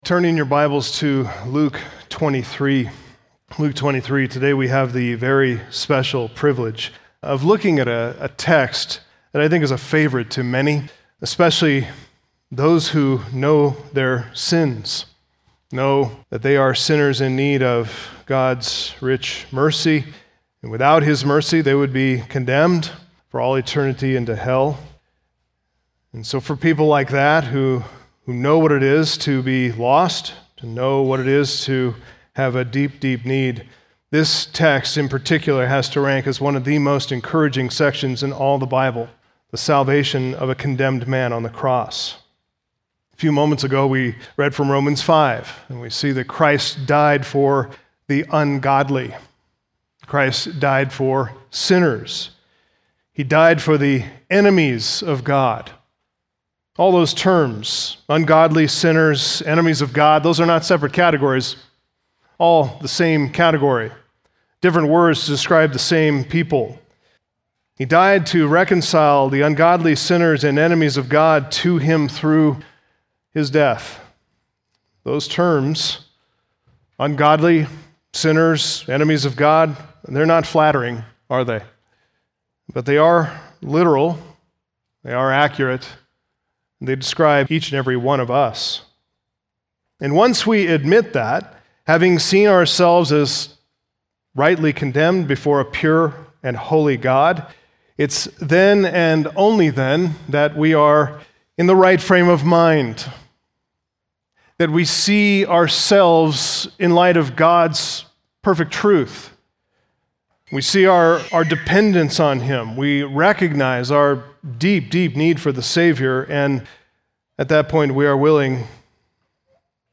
Sermons The Gospel of Luke